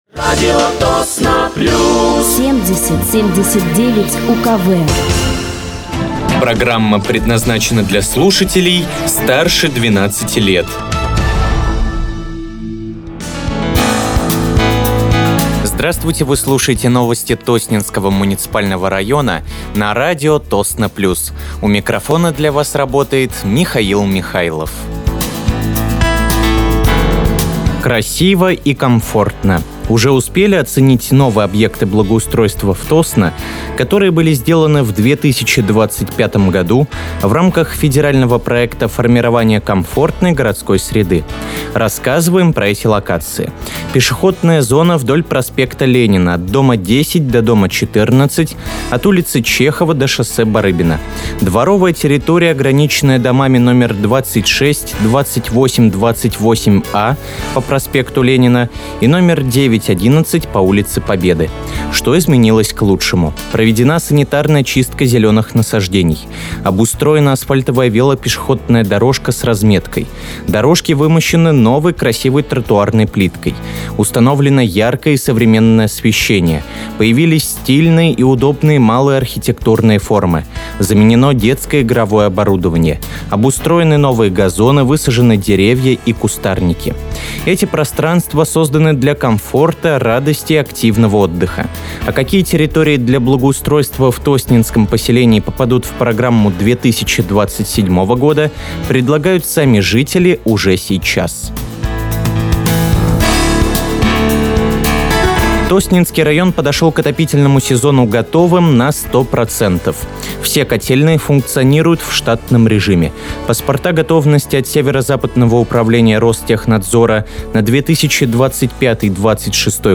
Вы слушаете новости Тосненского муниципального района на радиоканале «Радио Тосно плюс».